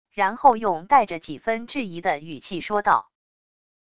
然后用带着几分质疑的语气说道wav音频